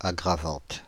Ääntäminen
Ääntäminen France (Île-de-France): IPA: /a.ɡʁa.vɑ̃t/ Haettu sana löytyi näillä lähdekielillä: ranska Käännöksiä ei löytynyt valitulle kohdekielelle.